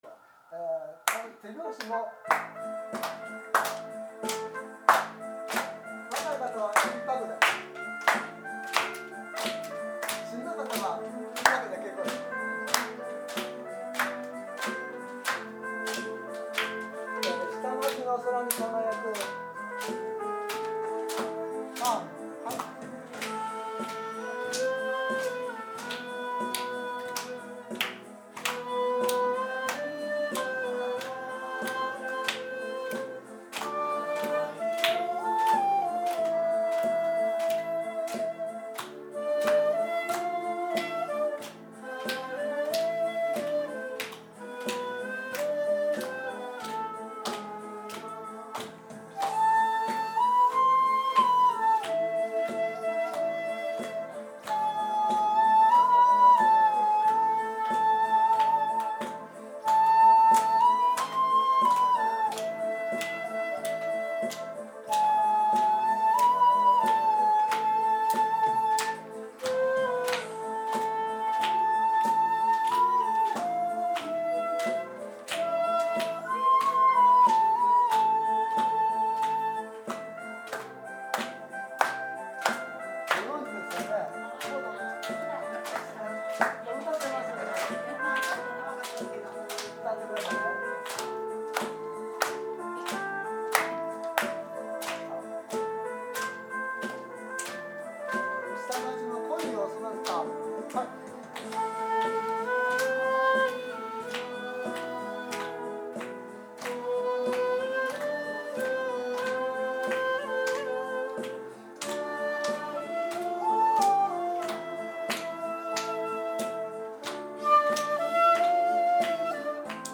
（グループホームでのボランティア演奏：音源）
カラオケCDを持ってラジカセで再生して尺八をそれに合わすというものです。